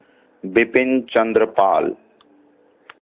pronunciation; 7 November 1858 – 20 May 1932) was an Indian nationalist, writer, orator, social reformer and freedom fighter.